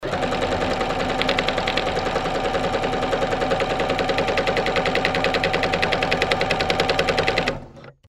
На этой странице собраны реалистичные звуки работы швейной машины.
Звук работающей швейной машинки